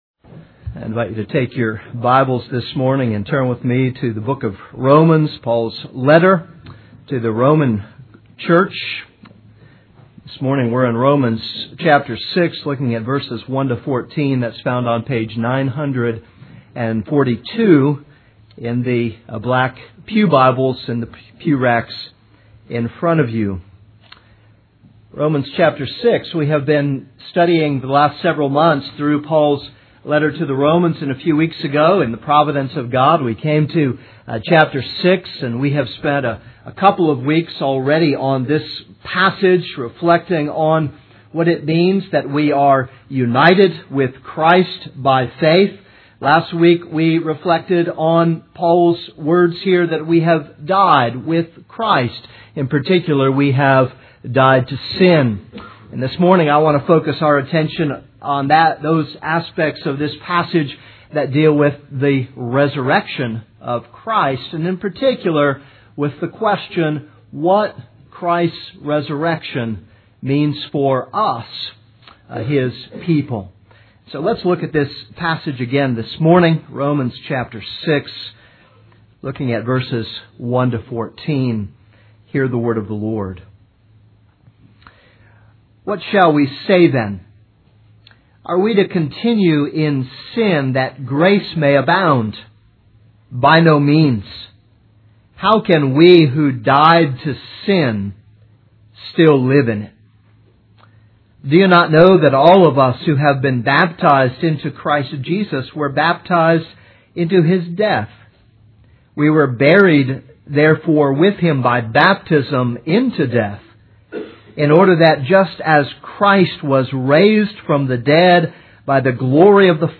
This is a sermon on Romans 6:1-14.